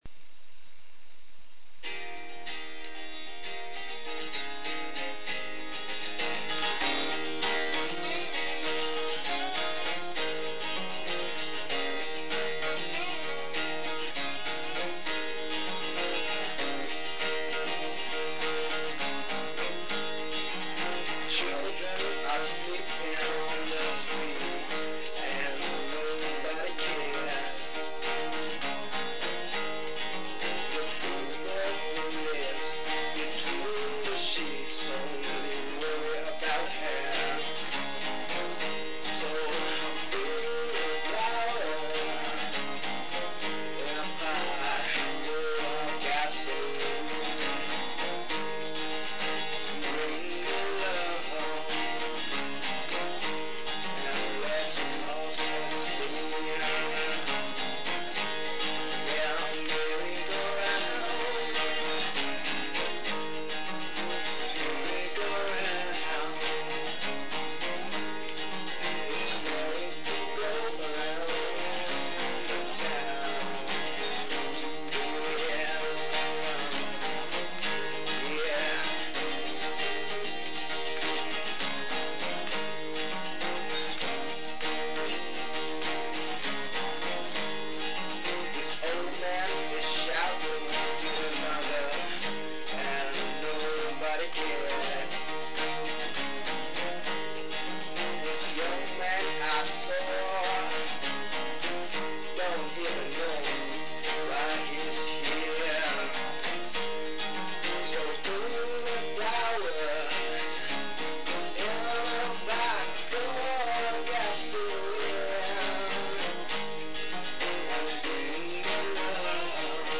Here's some song that we recorded ourselves.